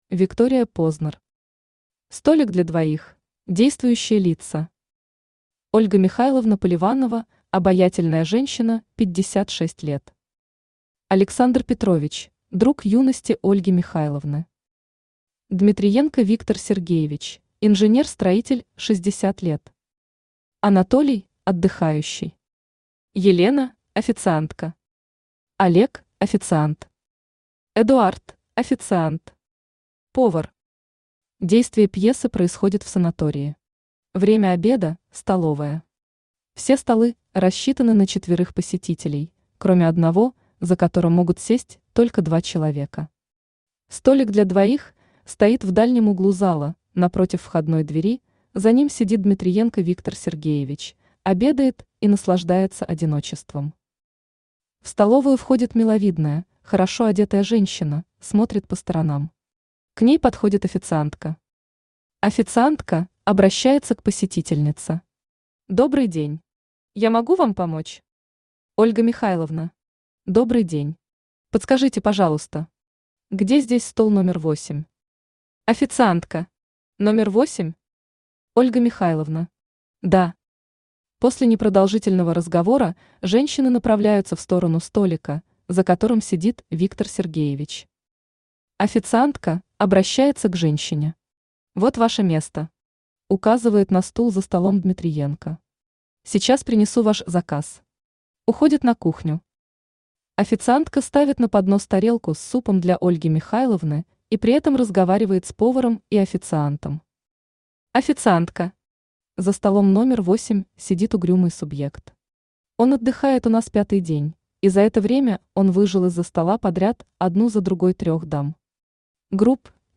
Аудиокнига Столик для двоих | Библиотека аудиокниг
Aудиокнига Столик для двоих Автор Виктория Познер Читает аудиокнигу Авточтец ЛитРес.